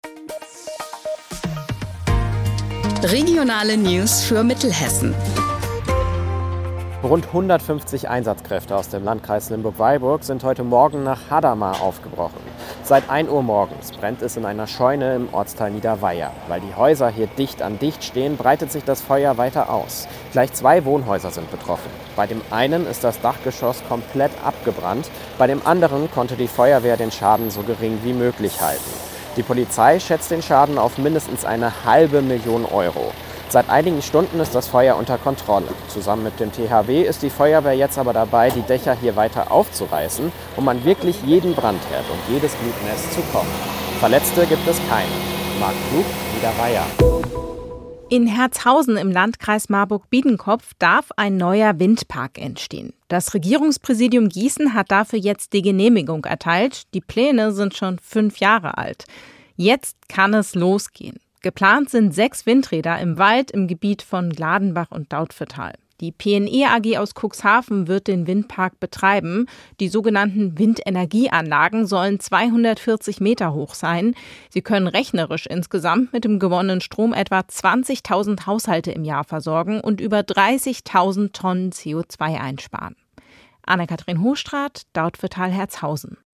Mittags eine aktuelle Reportage des Studios Gießen für die Region